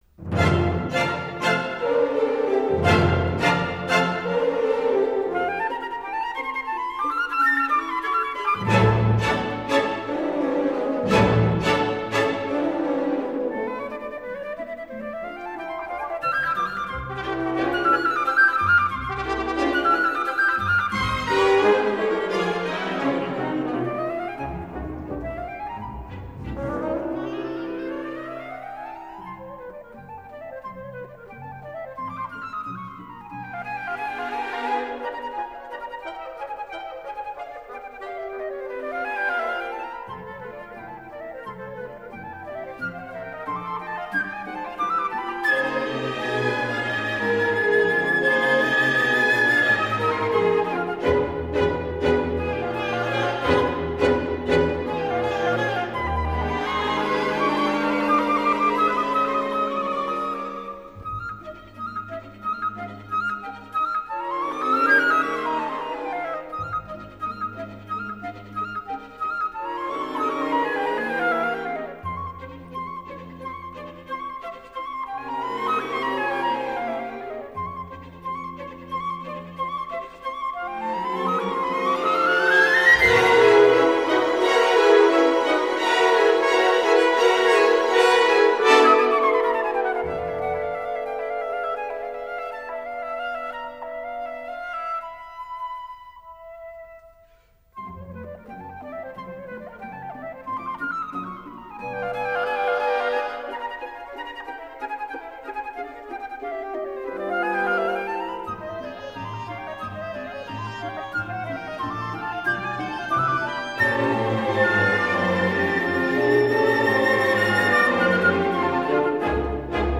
Nel ricordarlo, abbiamo raccolto la testimonianza di Franco Cesarini , suo allievo e oggi compositore e direttore apprezzato a livello internazionale.